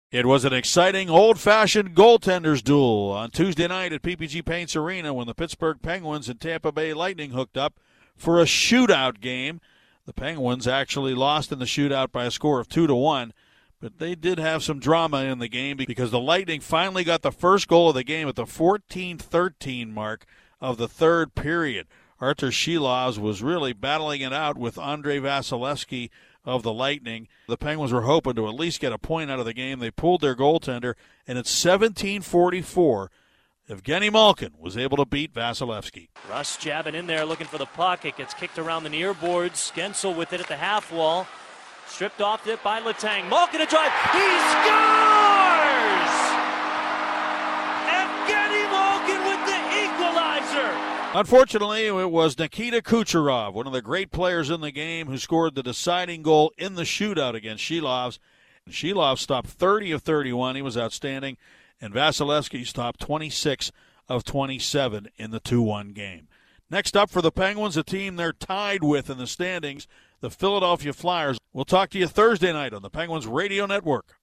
The Penguins took a third straight loss last night, still struggling to find offense against the red-hot Tampa Bay Lightning.   Paul Steigerwald has the recap.